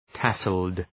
Προφορά
{‘tæsəld}